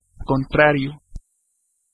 Ääntäminen
Vaihtoehtoiset kirjoitusmuodot (rikkinäinen englanti) hopposite (vanhahtava) opposit Synonyymit antonym reciprocal contradictory additive inverse Ääntäminen US UK : IPA : /ˈɒpəzɪt/ US : IPA : /ˈɑpəsɪt/